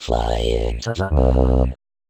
VVE1 Vocoder Phrases
VVE1 Vocoder Phrases 18.wav